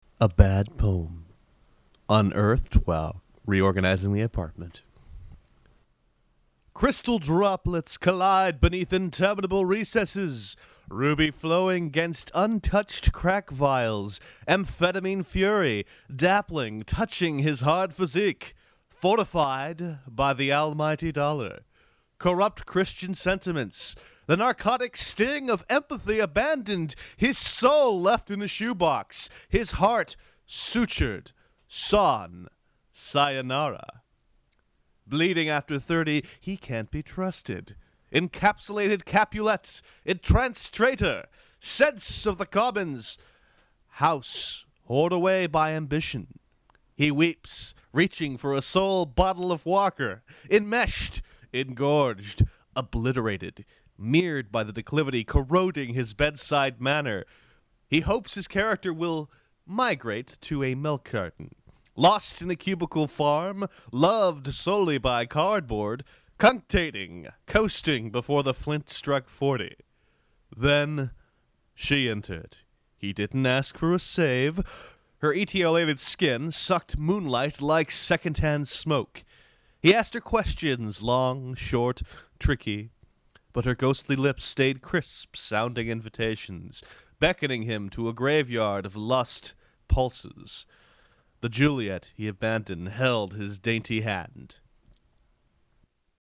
badpoem.mp3